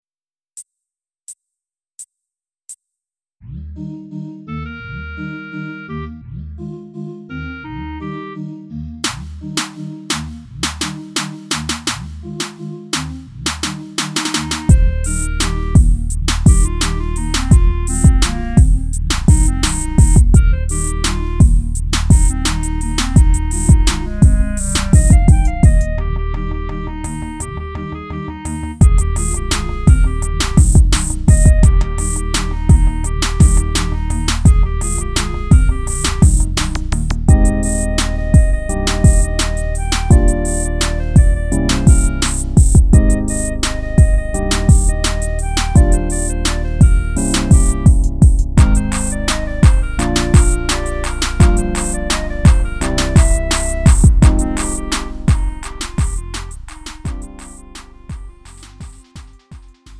음정 -1키 3:31
장르 가요 구분